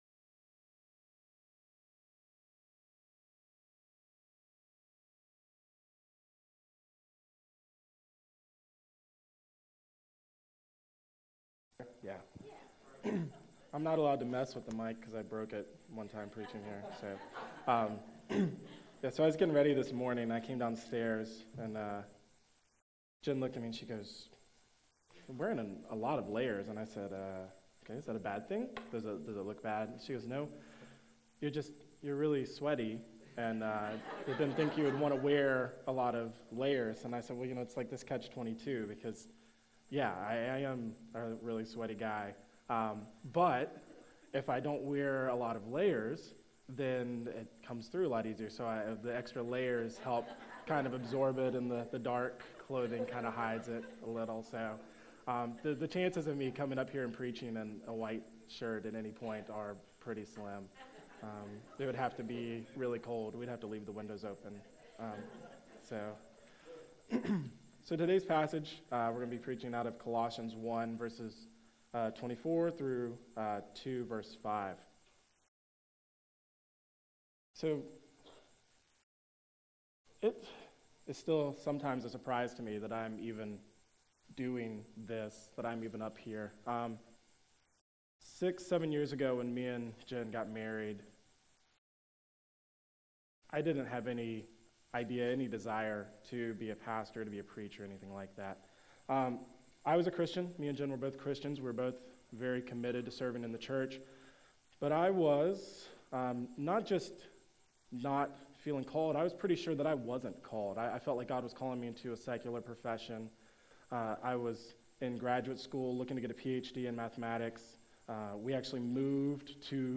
February 24, 2013 AM Worship | Vine Street Baptist Church